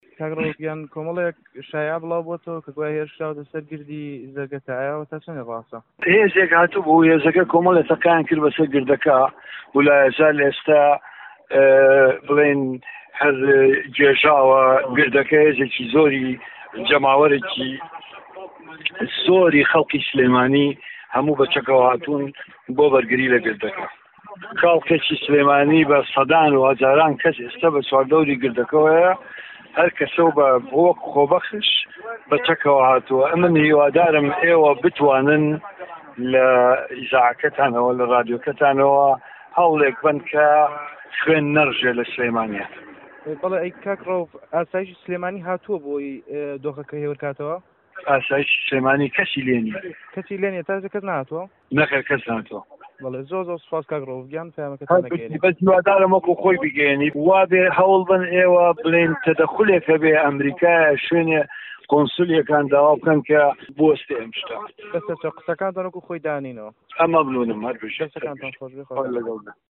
لێدوانی